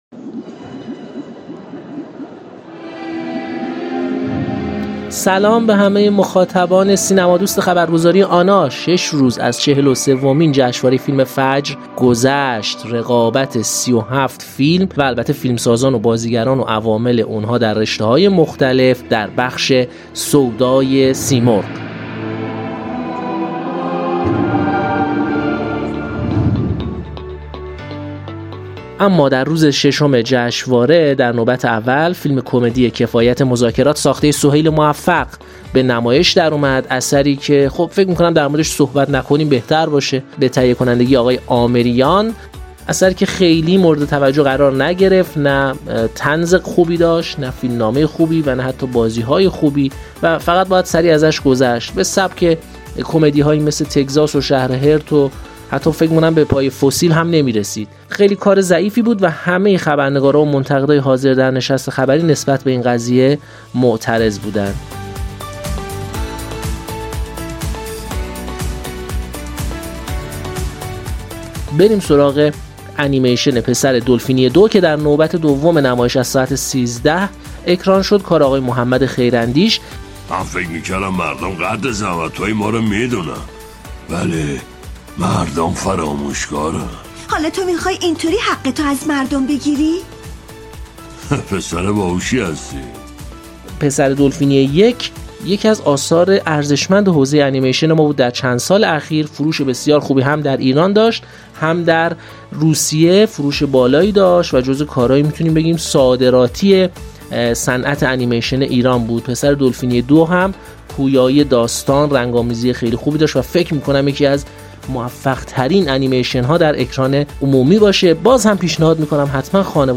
گزارش و اجرا